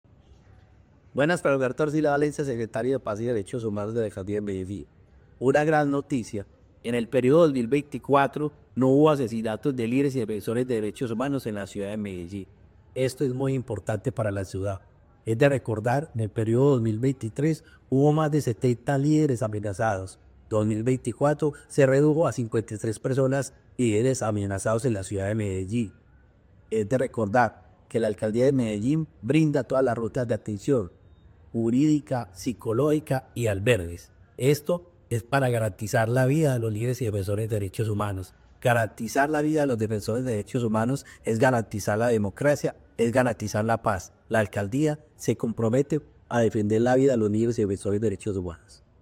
Palabras de Carlos Alberto Arcila, secretario de Paz y Derechos Humanos